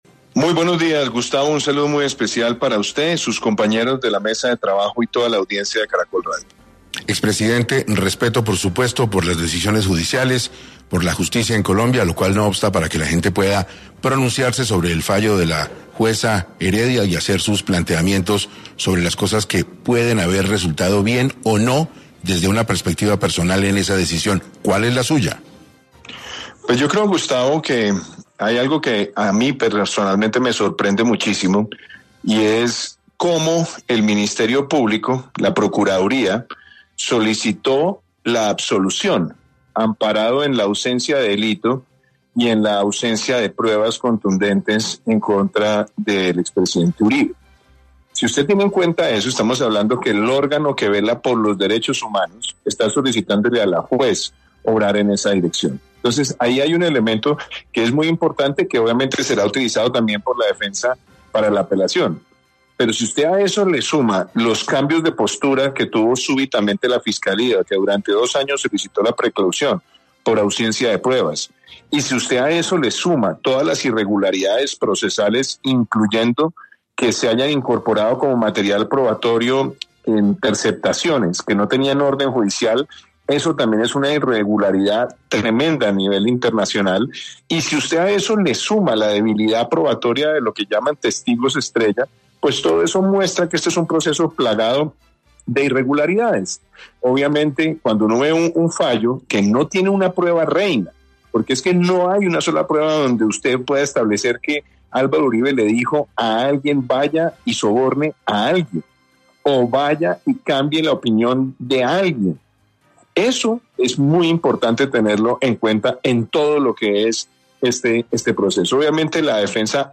En 6AM de Caracol Radio estuvo el exmandatario Iván Duque, quien advirtió que es fundamental que el caso Uribe sea llevado al exterior.